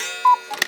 cuckoo_strike.wav